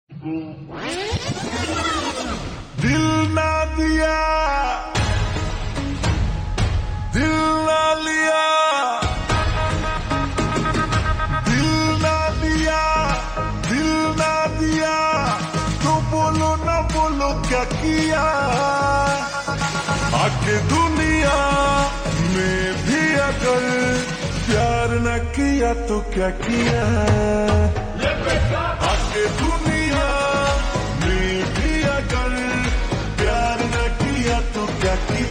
Bhojpuri Songs
Slow Reverb Version
• Simple and Lofi sound